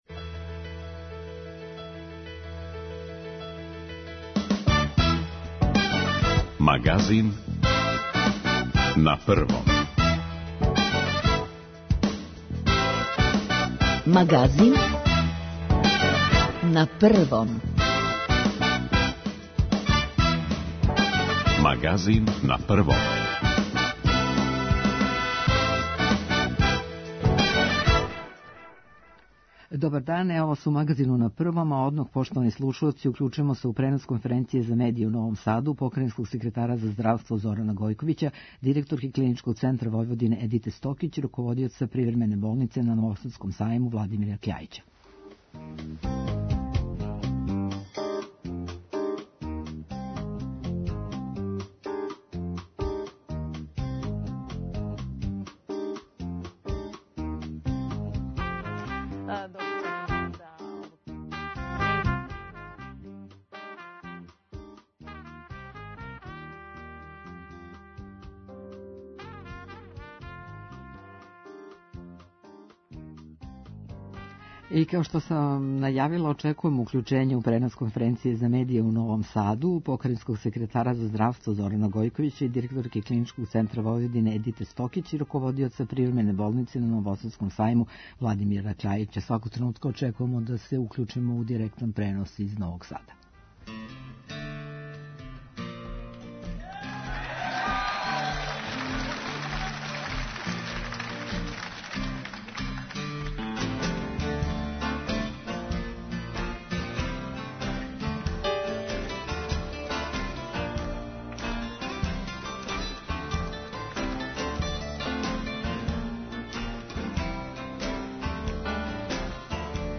Гост емисије министар пољопривреде и члан Кризног штаба за југ Србије Бранислав Недимовић говори о помоћи државе пољопривредницима.